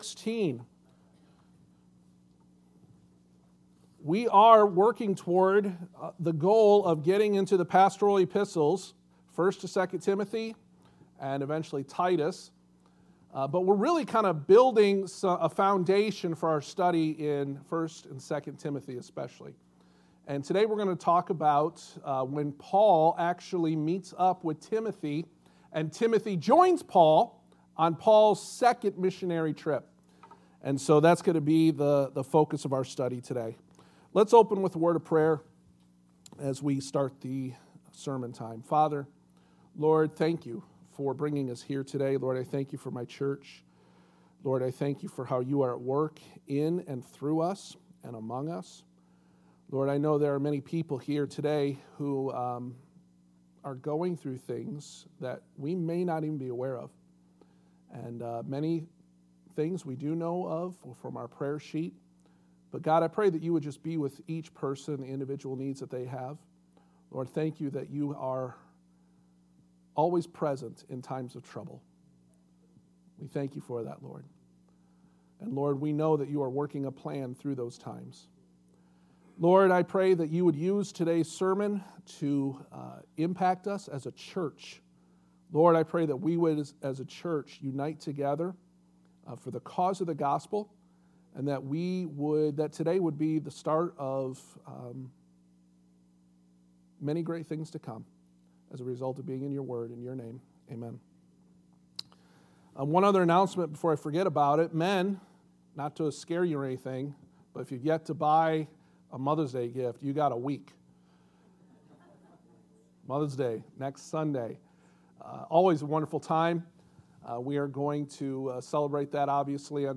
The Pastoral Epistles Passage: Acts 16:1-5 Service Type: Sunday Morning « Engaging and Evangelizing